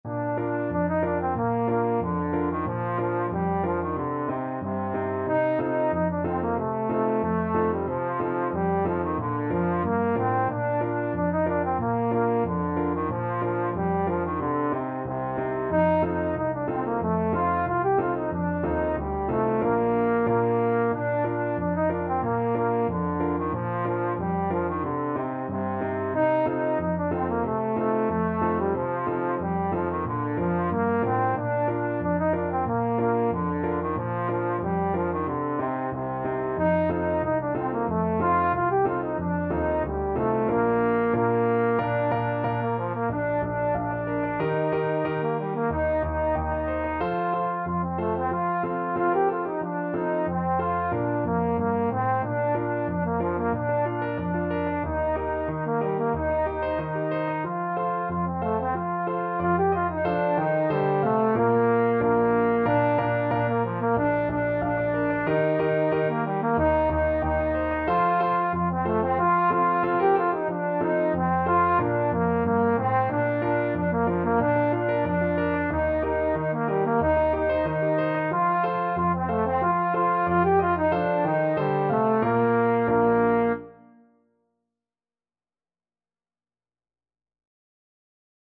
Trombone
Traditional Music of unknown author.
2/4 (View more 2/4 Music)
Moderato =c.92
Bb major (Sounding Pitch) (View more Bb major Music for Trombone )
Danish